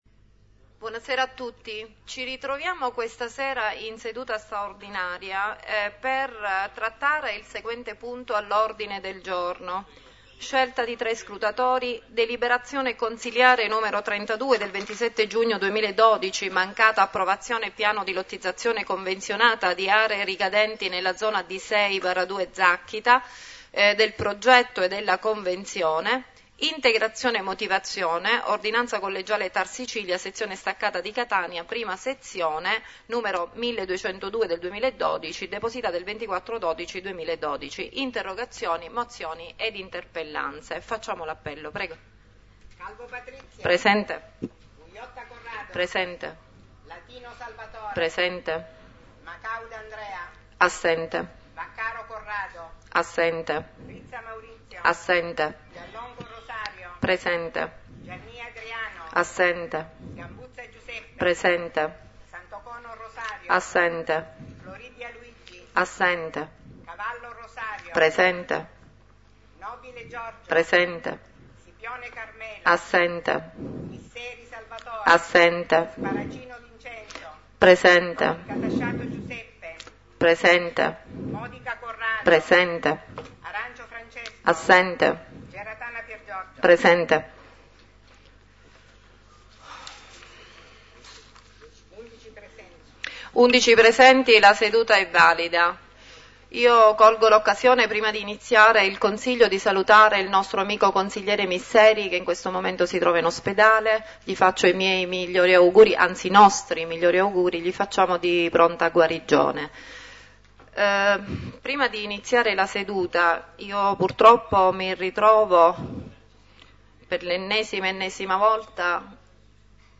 consiglio comunale 04.02.2013